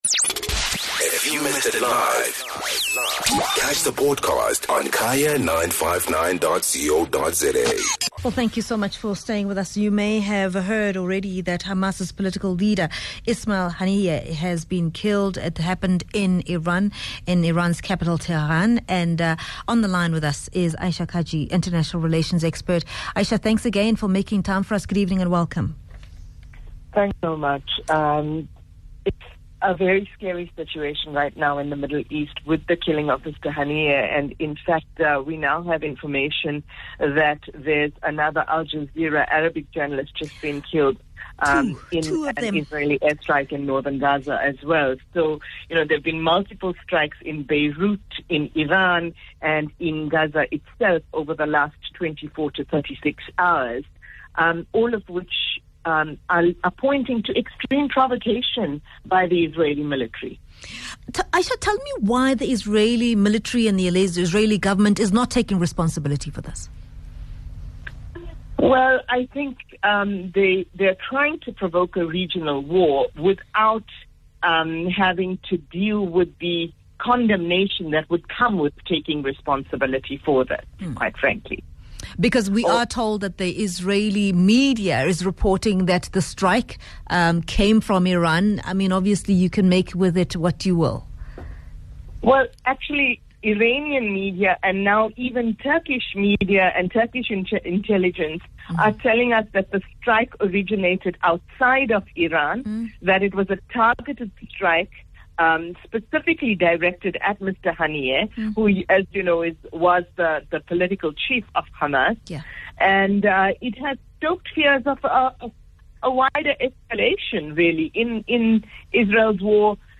International Relations Expert